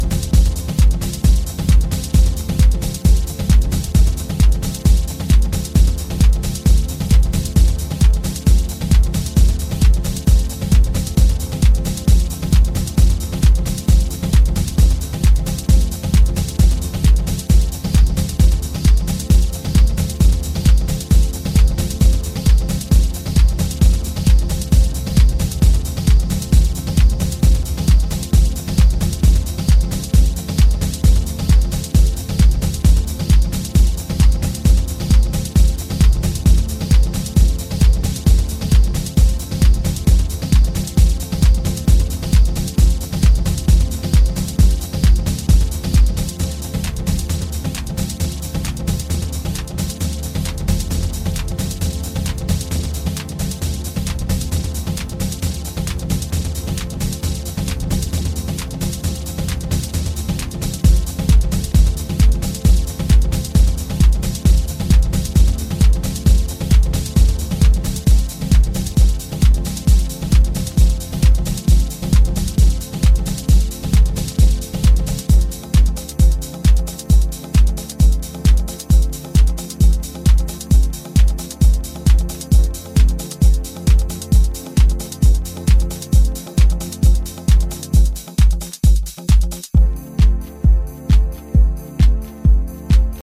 Detroit Techno